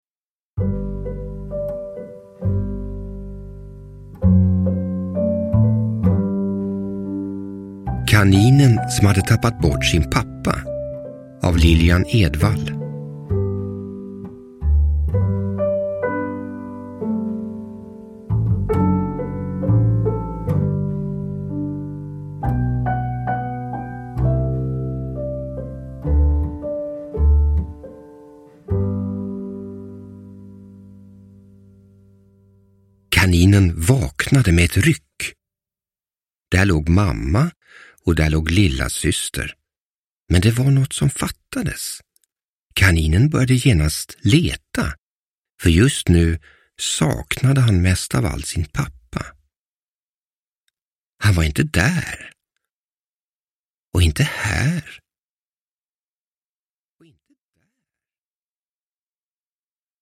Kaninen som hade tappat bort sin pappa – Ljudbok – Laddas ner